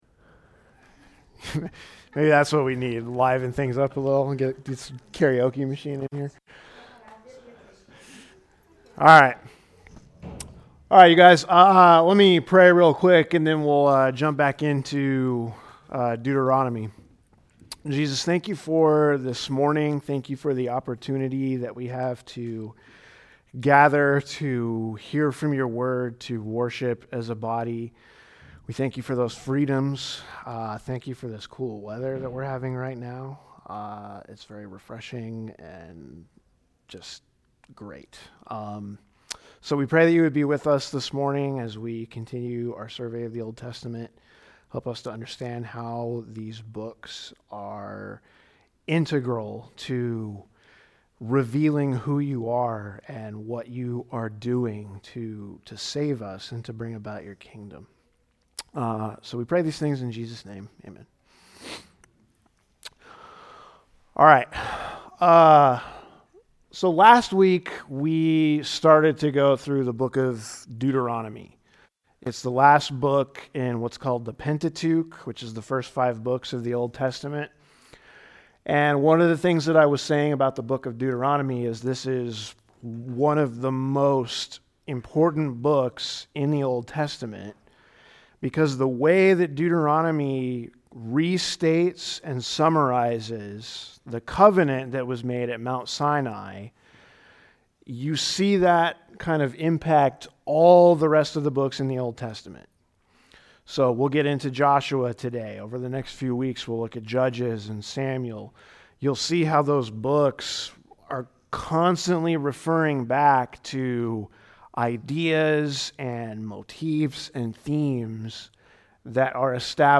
A message from the series "Old Testament Survey."